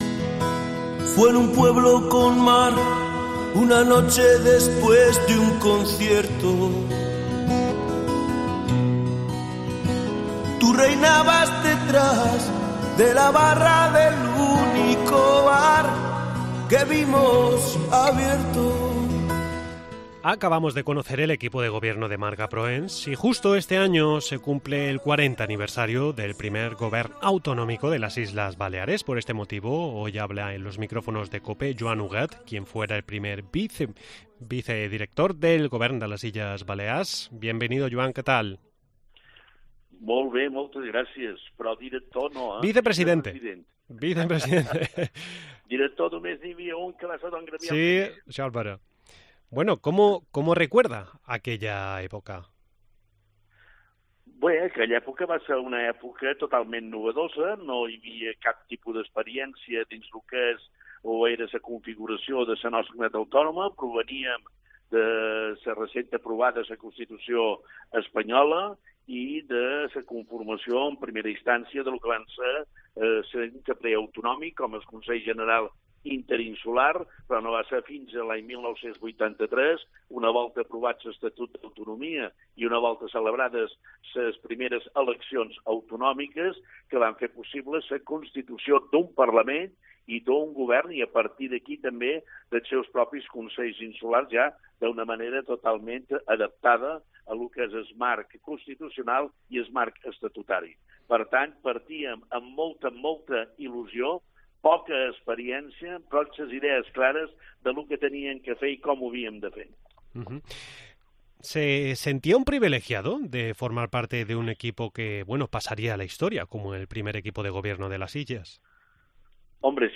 Por este motivo hoy habla en los micrófonos de cope Joan Huguet, quien fuera el primer vicepresidente del Govern de las Islas Baleares.